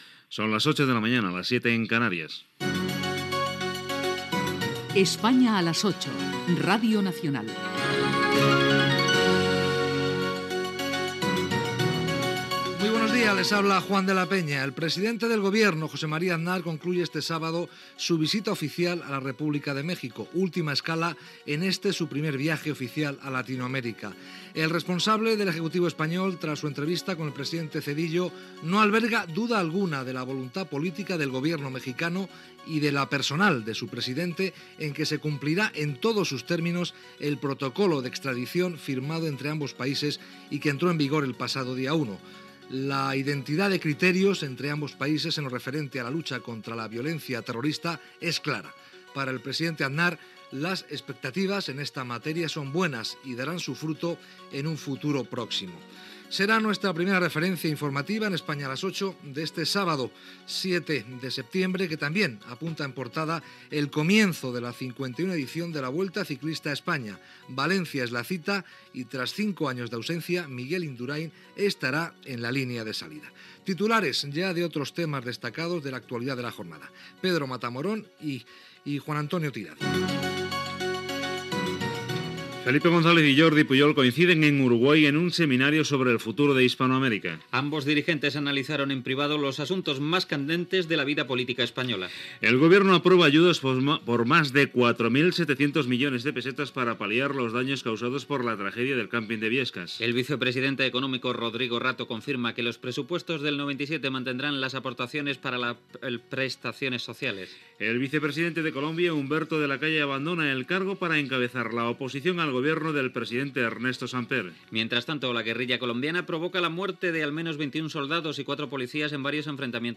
crònica des de Mèxic i declaracions del president espanyol José María Aznar.